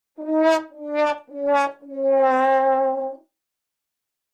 Звуки провала, неудачи
Звук трубы, означающий провальный момент